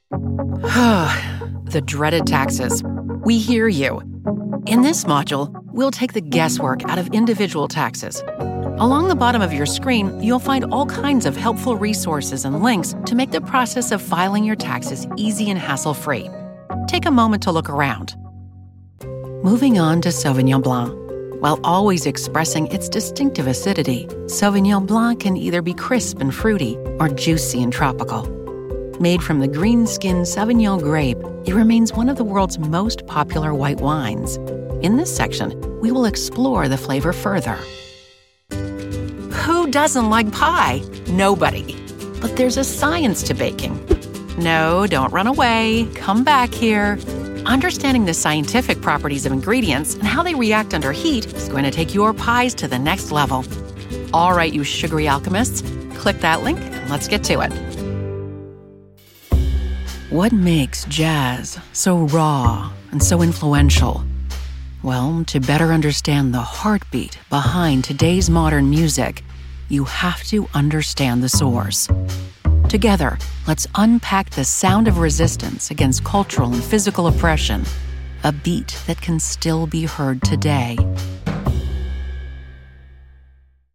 Apprentissage en ligne
Une voix chaleureuse et attentionnée pour des publicités pleines de compassion.
Avec une cabine StudioBricks et un micro Sennheiser, je produis efficacement un son de qualité studio.
Sennheiser 416 ; cabine StudioBricks ; Adobe Audition ; mixeur Yamaha AG03 ; Source-Connect.